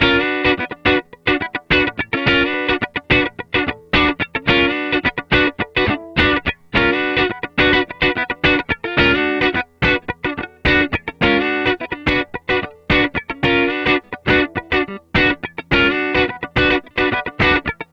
DF_107_E_FUNK_GTR_02.wav